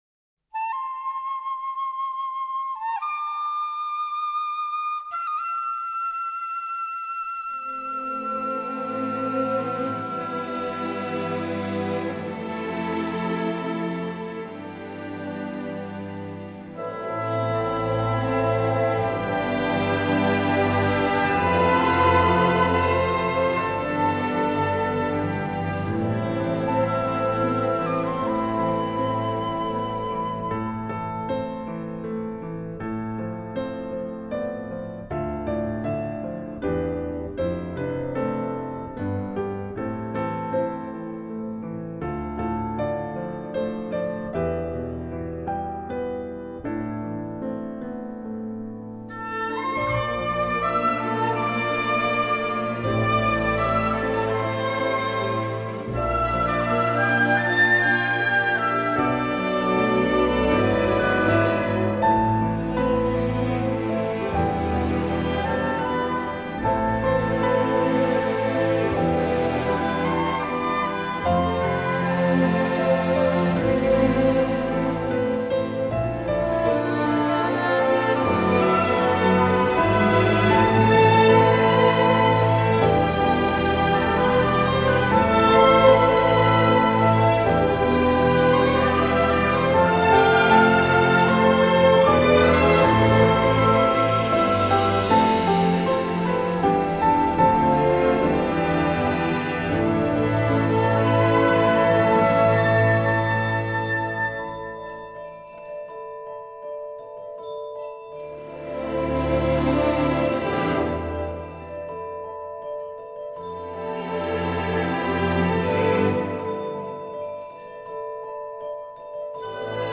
气势磅礴,扣人心弦的电影原声乐及主题曲